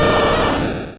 Cri de Carabaffe dans Pokémon Rouge et Bleu.